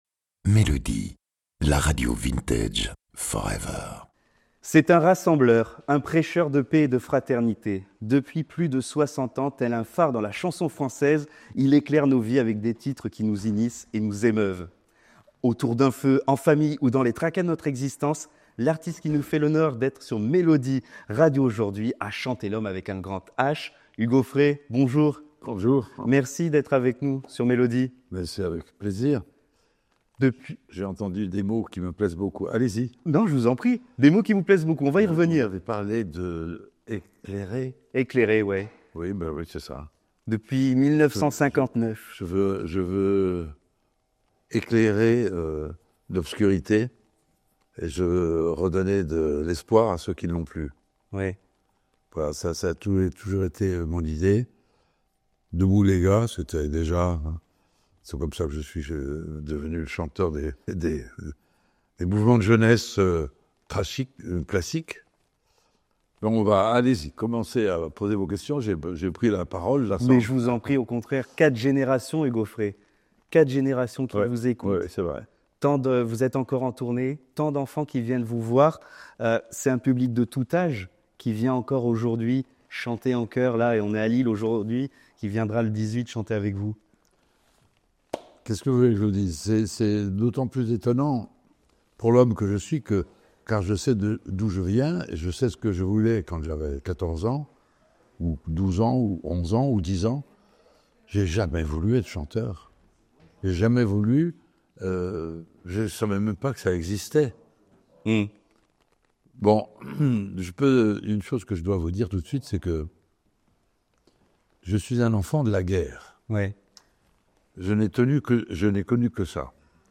Dans cet entretien exceptionnel pour Melody Radio, Hugues Aufray revient sur un parcours hors du commun : une enfance traversée par la guerre, des rêves de devenir peintre, et une voix devenue celle d’un rassembleur, d’un passeur d’humanité.